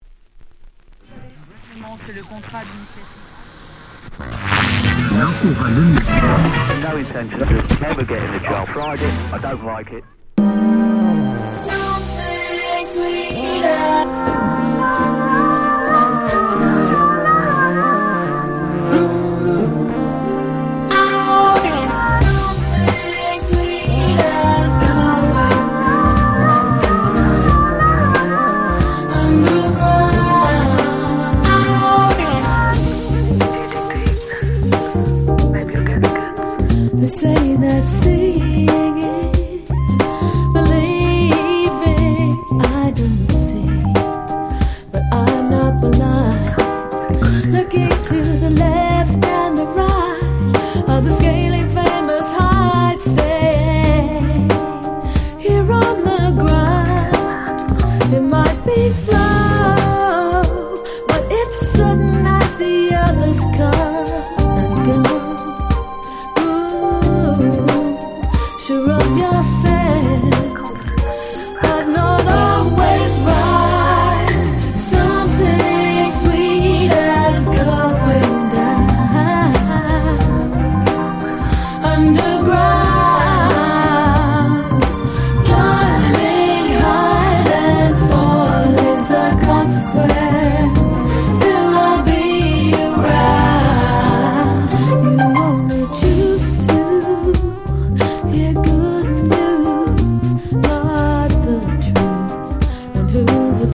最高のUK Soul Albumです。